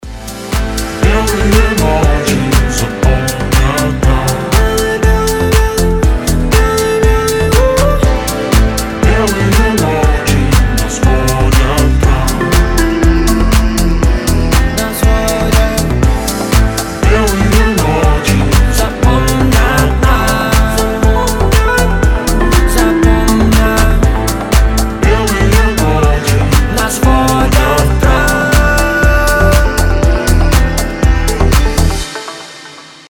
поп
dance
чувственные
летние
house